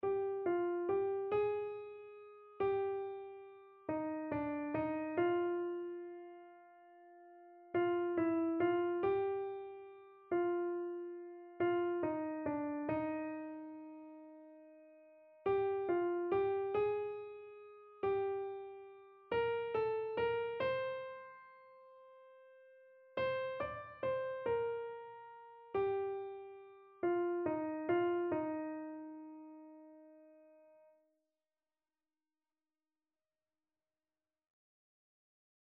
Keyboard version
Christian
Free Sheet music for Keyboard (Melody and Chords)
9/4 (View more 9/4 Music)
Keyboard  (View more Easy Keyboard Music)
Classical (View more Classical Keyboard Music)